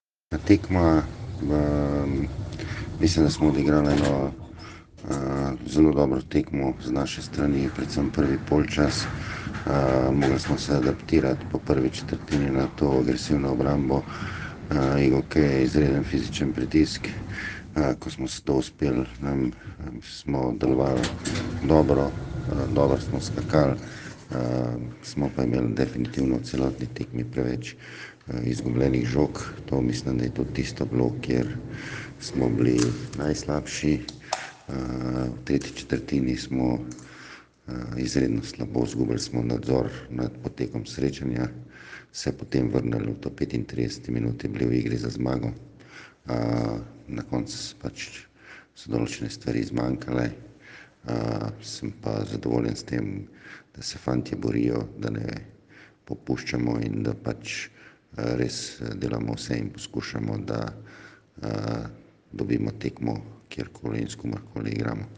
Izjavi po tekmi: